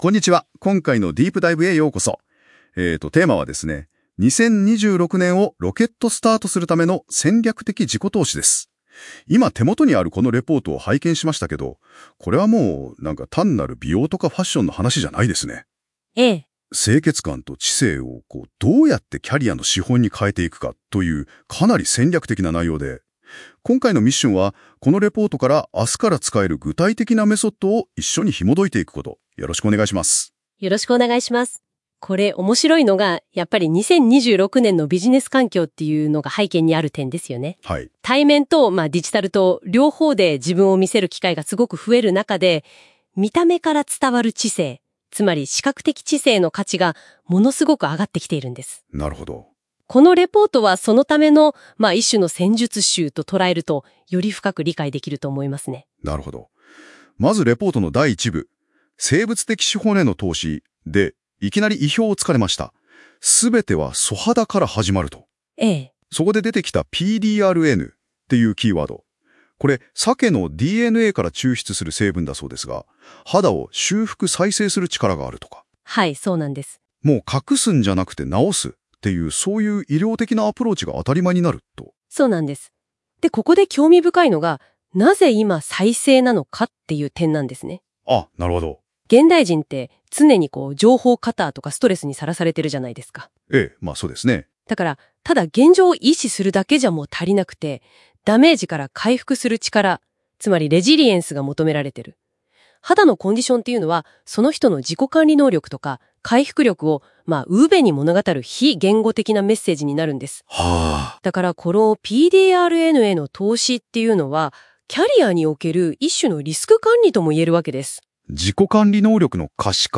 【音声解説】戦略的自己投資清潔感と視覚的知性